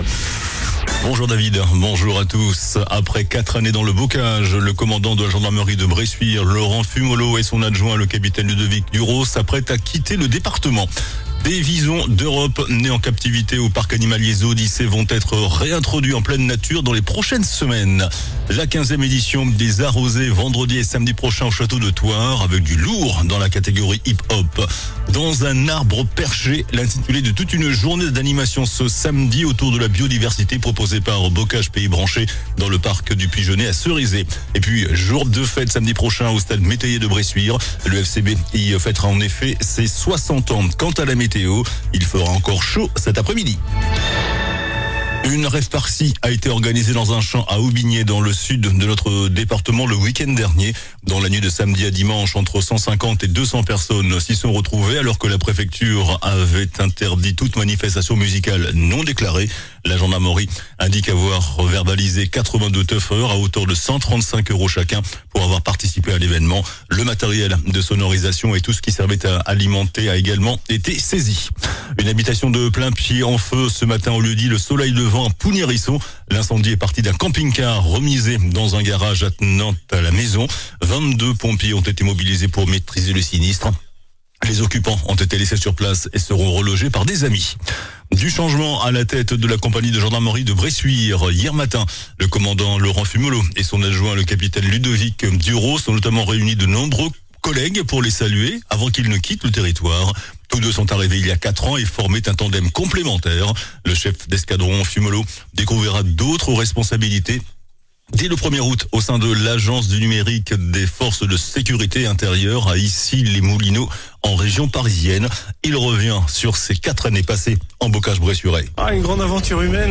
JOURNAL DU MARDI 14 JUIN ( MIDI )